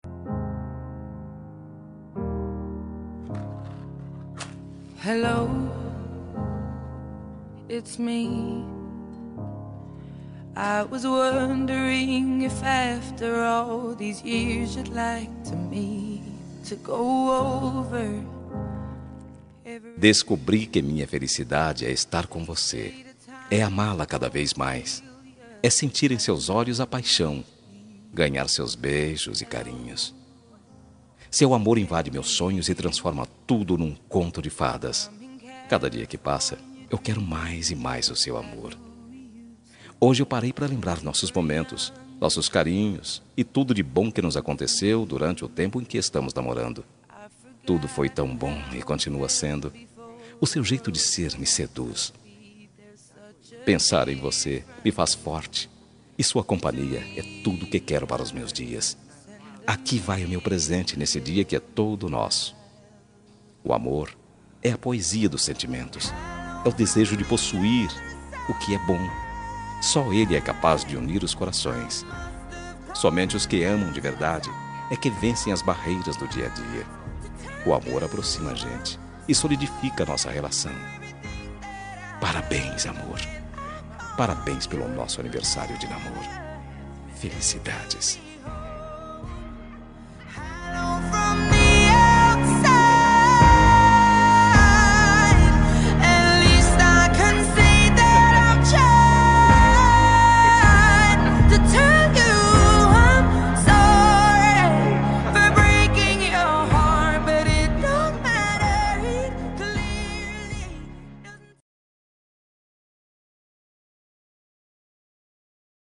Telemensagem Aniversário de Namoro – Voz Masculina – Cód: 8104- Linda.
8104-aniv-namoro-masc.m4a